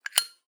zippo_open_02.wav